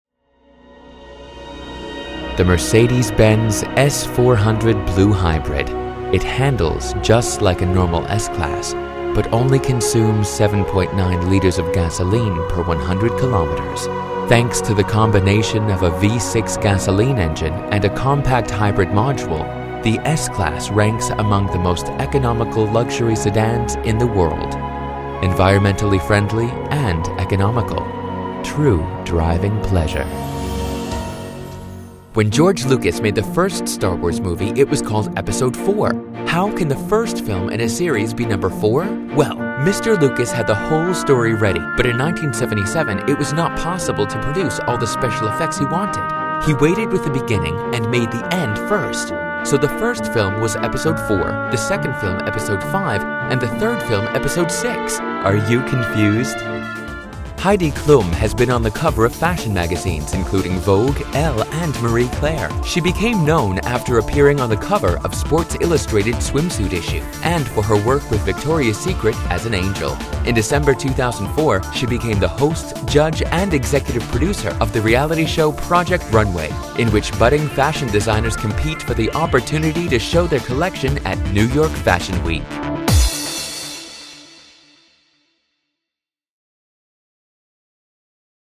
STUDIO: ISDN Centauri 3001 II Aptx Codec, Source Connect, Neumann TLM 103, TL Audio Ivory II Preamp, ProTools
Native US Sprecher mit einen freundlichen, frechen, guy next door Stimme für Werbung.
englisch (us)
Sprechprobe: Industrie (Muttersprache):